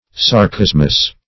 sarcasmous - definition of sarcasmous - synonyms, pronunciation, spelling from Free Dictionary
Search Result for " sarcasmous" : The Collaborative International Dictionary of English v.0.48: Sarcasmous \Sar*cas"mous\, a. Sarcastic.